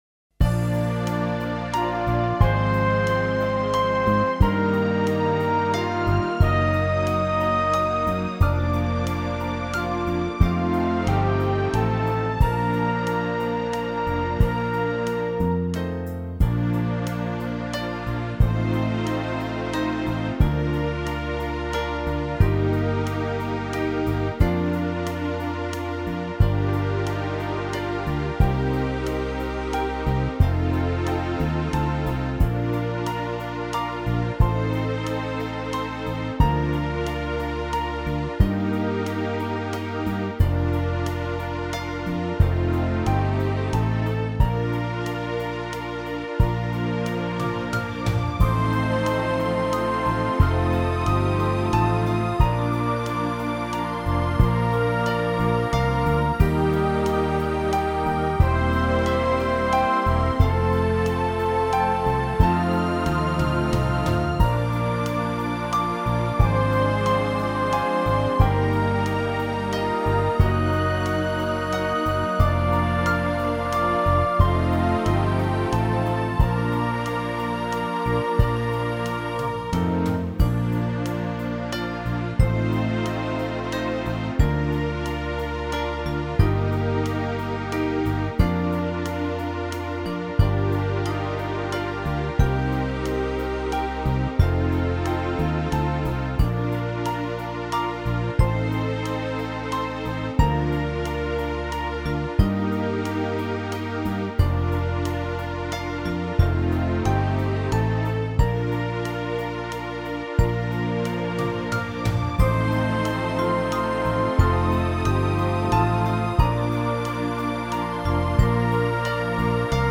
Enkla taktfasta